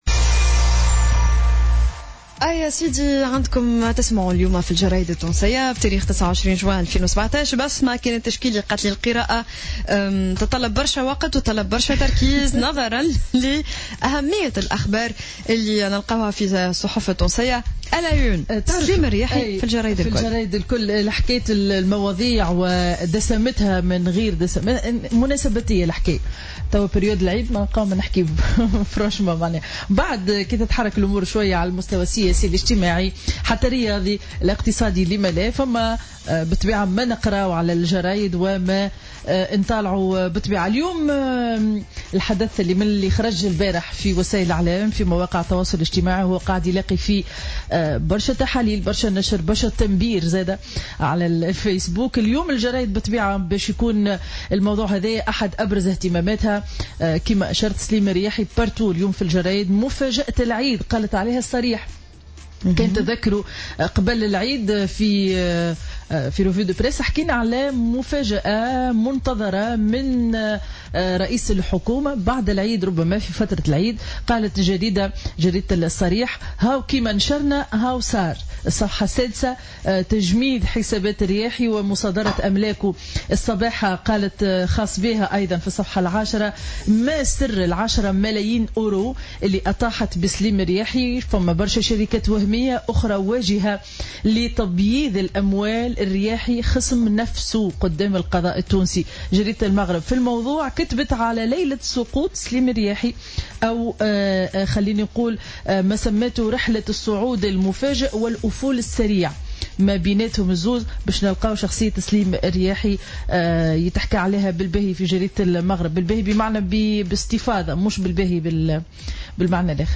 Revue de presse du jeudi 29 juin 2017